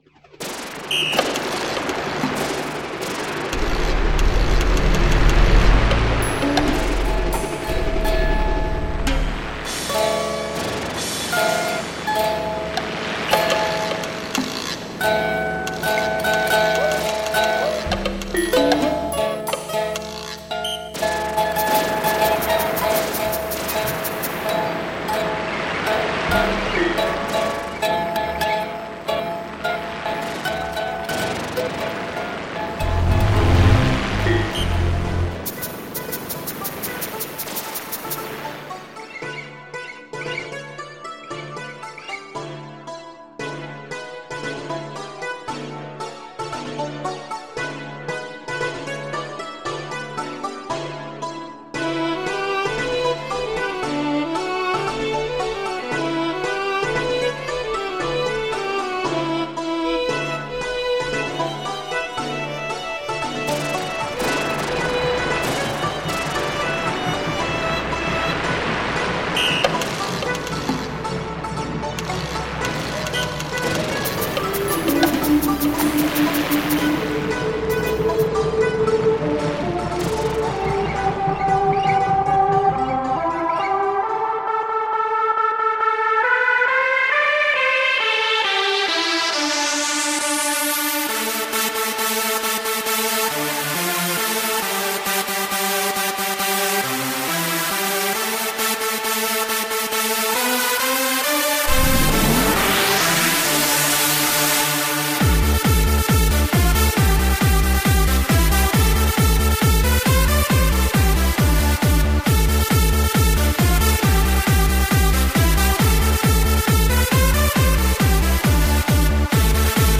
It differs from a “regular” Appalachian Dulcimer in that it has four playing strings and two drones running underneath.
Finally the choice of using a D minor chord was that the three topside drones would always be providing a wistful D minor backing to whatever notes were played on the melody string.
The two drones running through the sound box were easy enough to decide upon and are tuned to A and D so they will sympathetically vibrate with the playing strings.
Baddest_Dulcimer_Sample.mp3